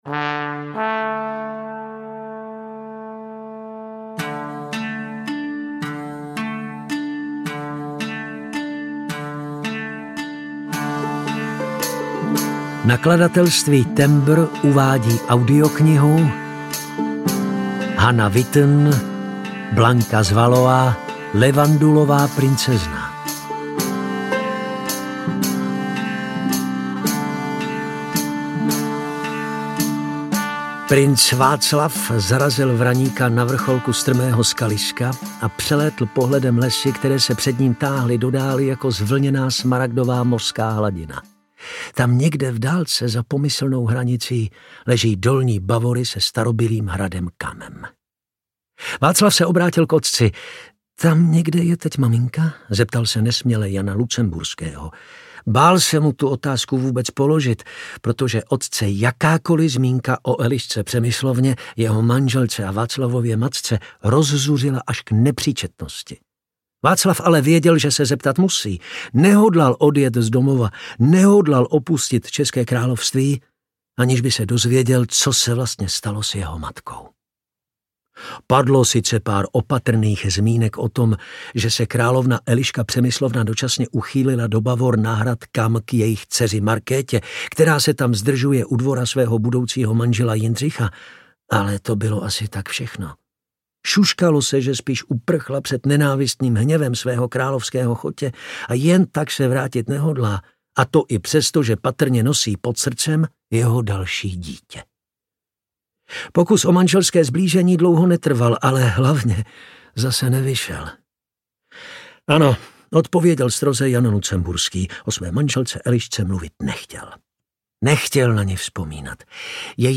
Blanka z Valois – Levandulová princezna audiokniha
Ukázka z knihy